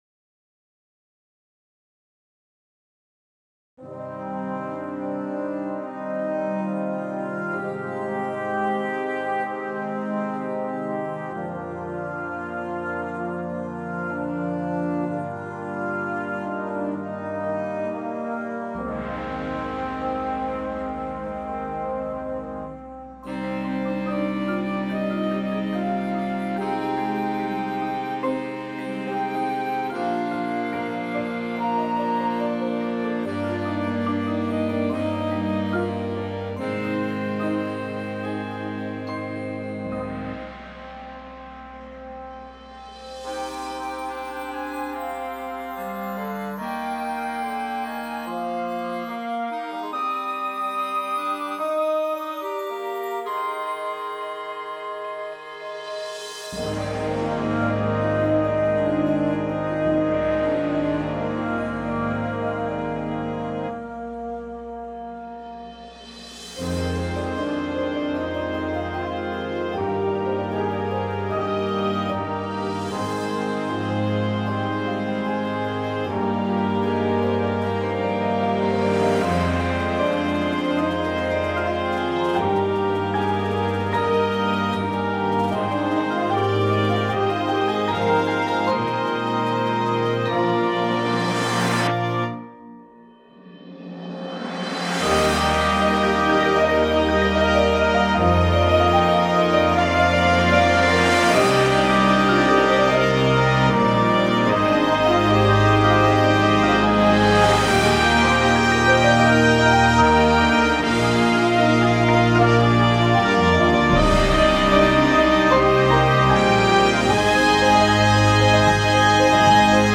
• Flute
• Alto Sax 1, 2
• Trumpet 1
• Trombone 1, 2
• Tuba
• Snare Drum
• Synthesizer – Two parts
• Marimba – Two parts